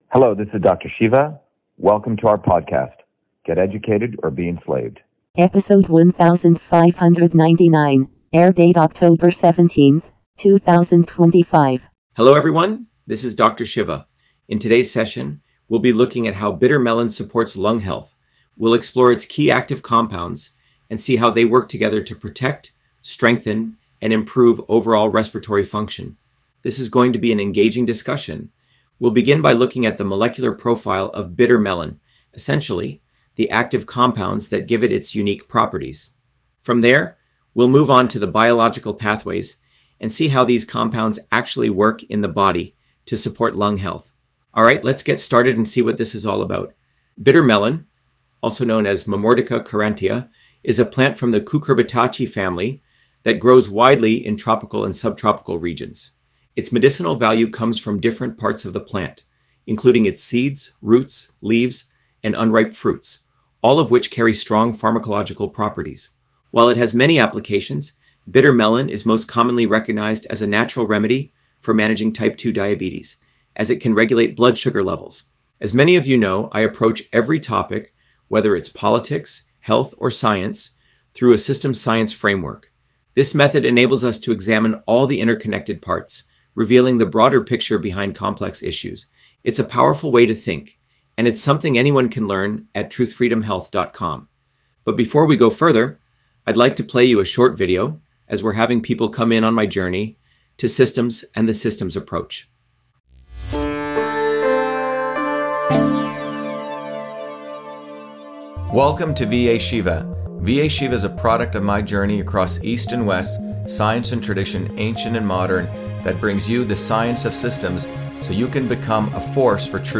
In this interview, Dr.SHIVA Ayyadurai, MIT PhD, Inventor of Email, Scientist, Engineer and Candidate for President, Talks about Bitter Melon on Lung Health: A Whole Systems Approach